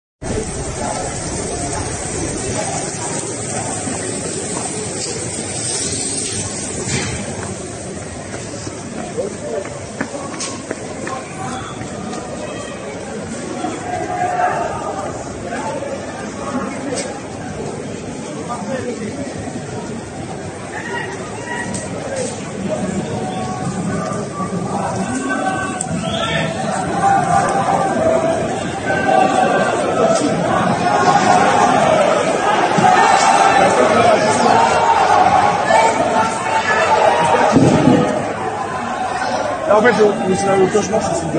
France Pension Protest - Fire on the Street